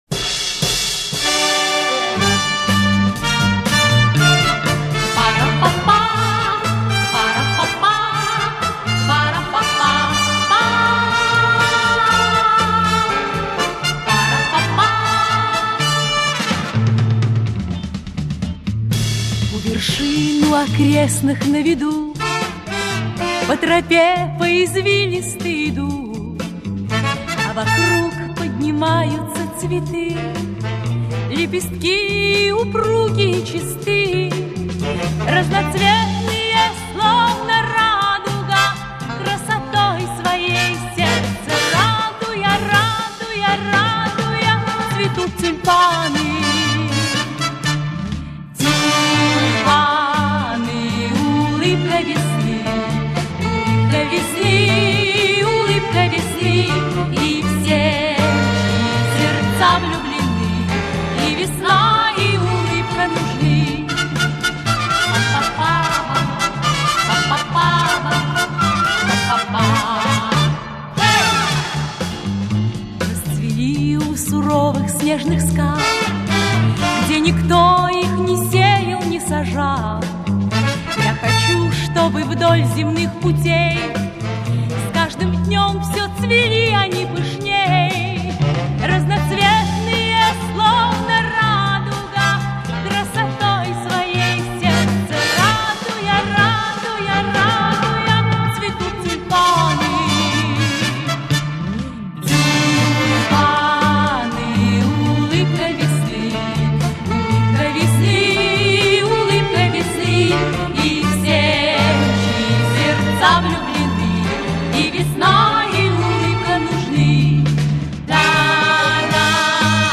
128, но звук нормальный.